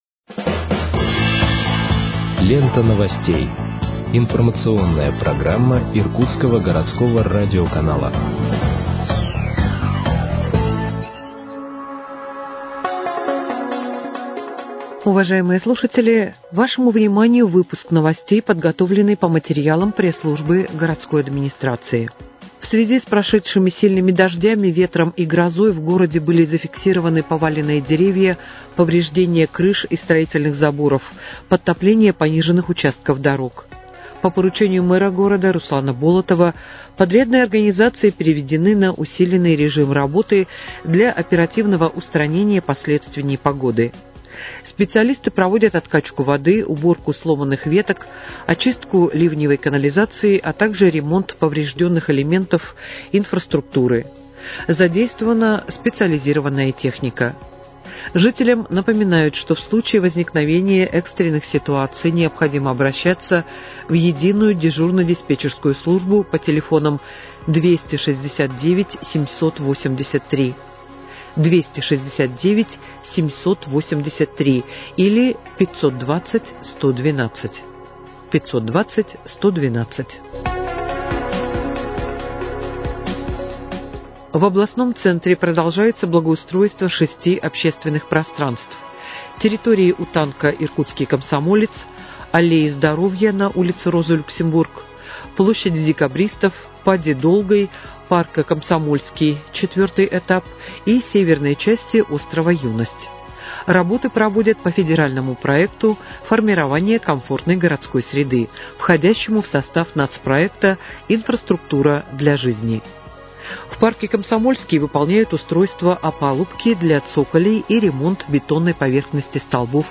Выпуск новостей в подкастах газеты «Иркутск» от 17.07.2025 № 1